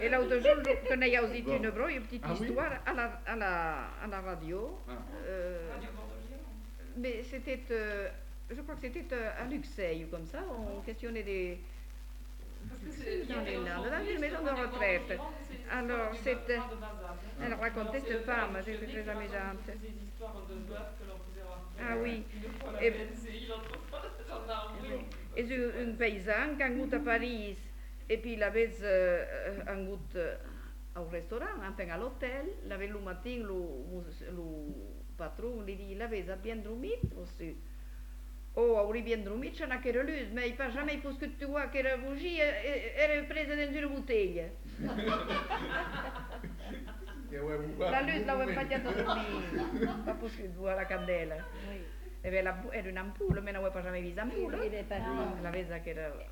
Lieu : Uzeste
Genre : conte-légende-récit
Type de voix : voix de femme
Production du son : parlé
Classification : récit anecdotique